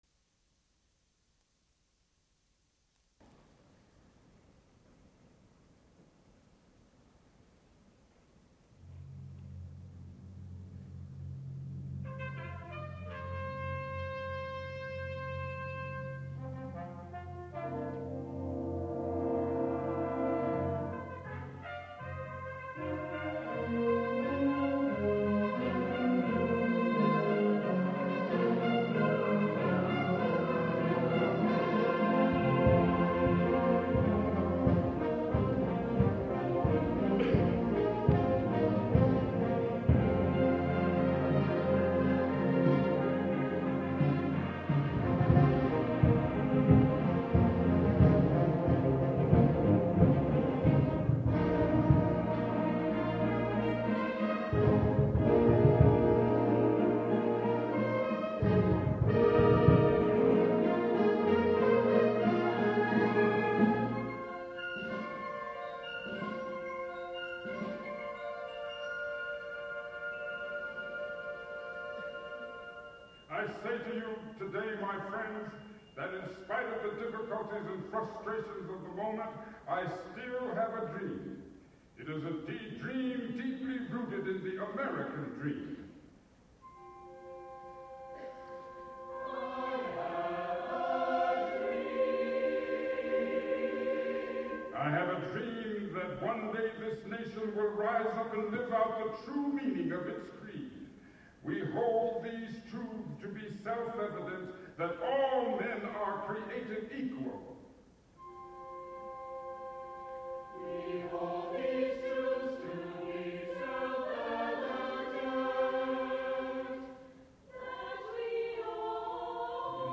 an instrumental fanfare
with instrumental and choral responses
for chorus and band